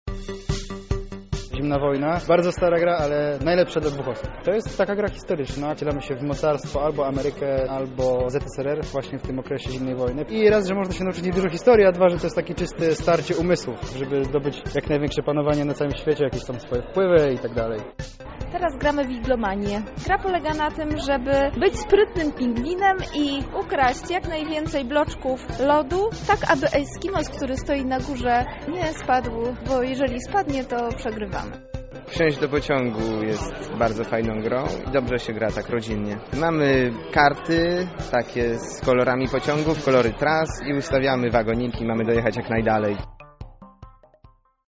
Zapytaliśmy uczestników wydarzenia, na jakie gry warto zwrócić uwagę: